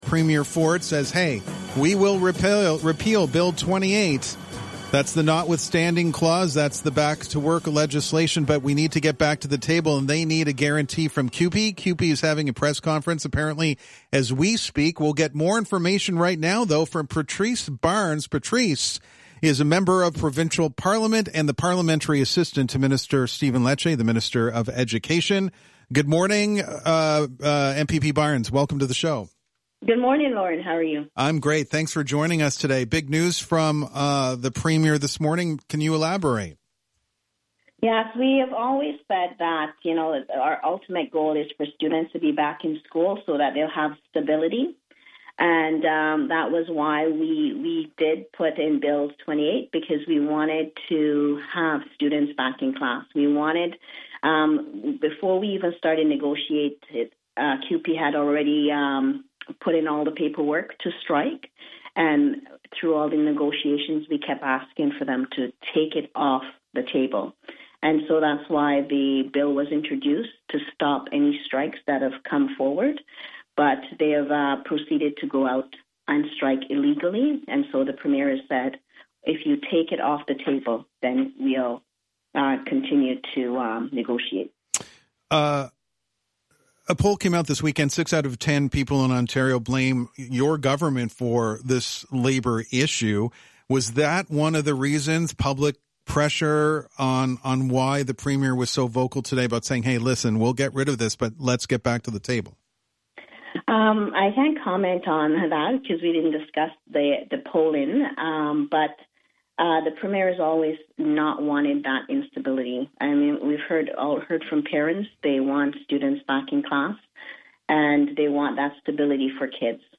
patrice-barnes-full-interview.mp3